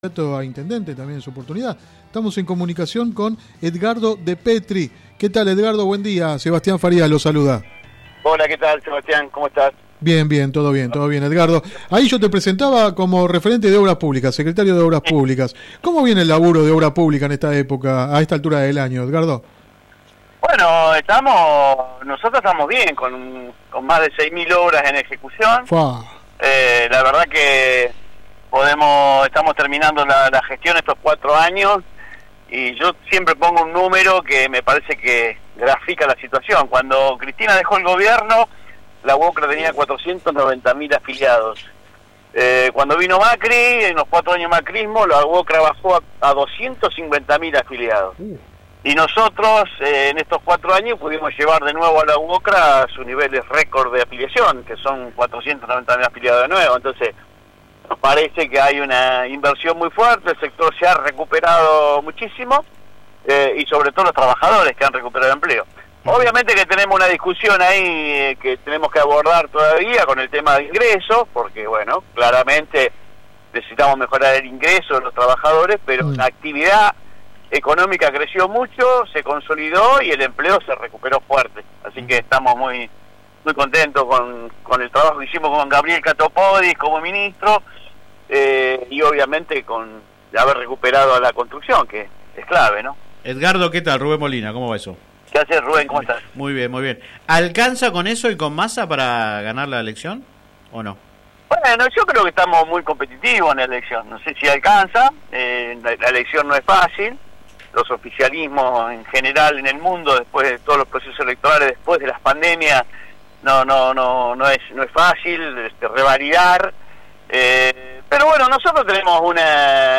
El Subsecretario de Ejecución de Obra Pública de la Nación, Edgardo Depetri, habló en el programa radial Sin Retorno (lunes a viernes de 10 a 13 por GPS El Camino FM 90 .7 y AM 1260).
Click acá entrevista radial